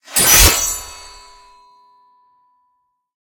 bsword3.ogg